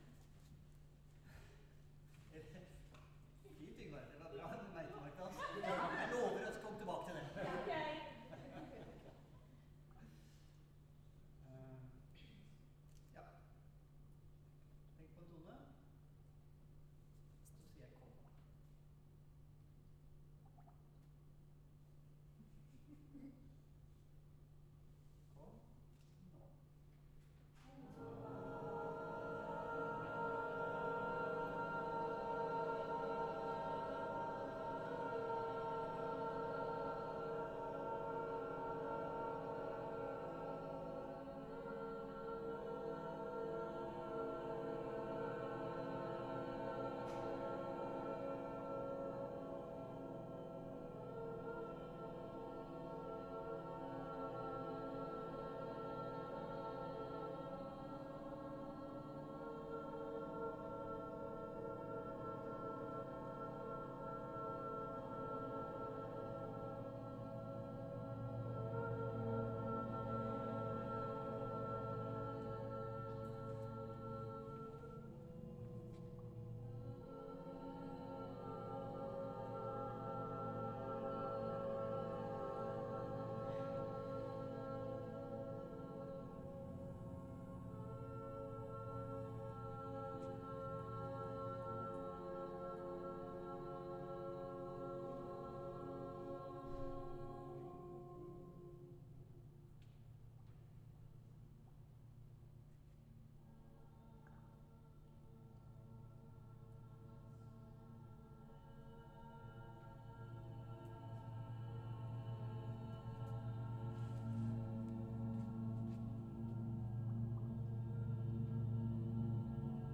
Lyder som ligger under eller over ørets radar transponerte vi ned i hørbart område.
Lydforløpet har tre deler: 1) Et cluster av våre stemmer live 2) Clusteret av menneskestemmer fader ut til fordel for lyder (stemmer?) av rommet 3) lyden av rommet spiller sammen med samples av stemmeclusteret vårt og live sang fra oss når vi synger et nytt cluster. Boblelydene på slutten (som vi først oppdaget når vi hørte opptaket) er fra biotopen!
stemmer-og-bygning-kr.sand-rc3a5dhus-1.wav